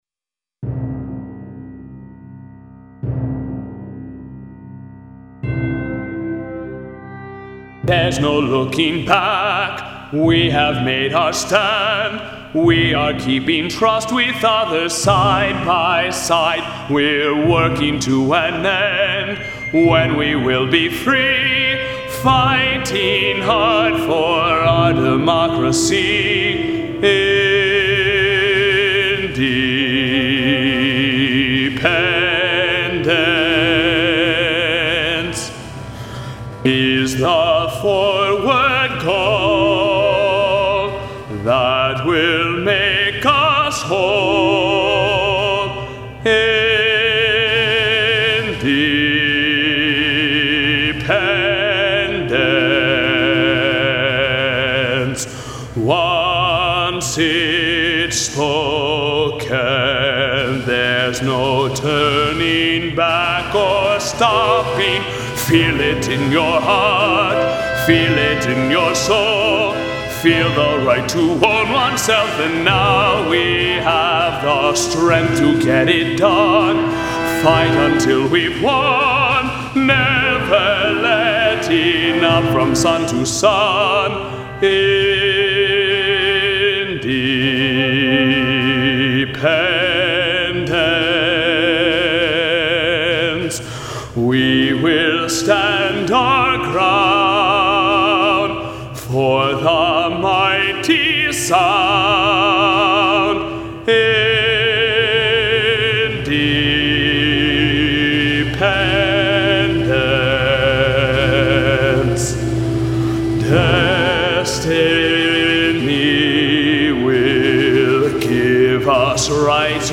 These recordings are rough demos.